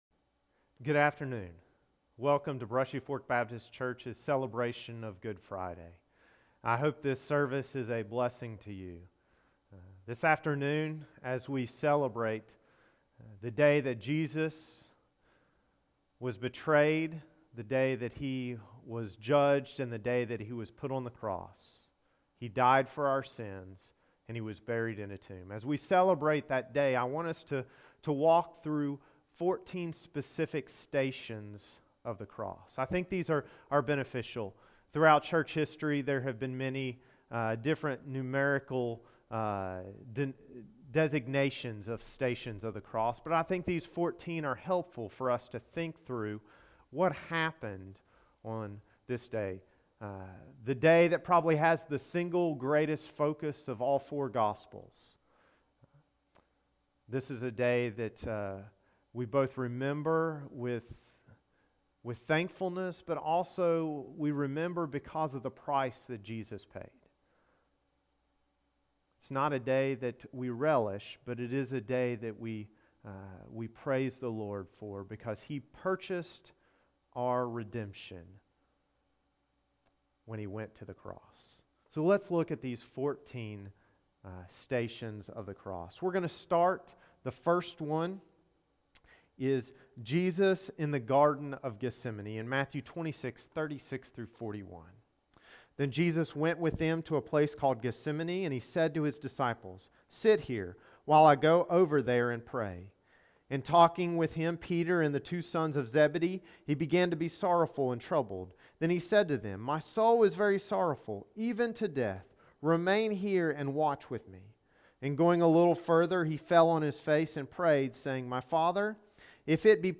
Week Four – Good Friday Service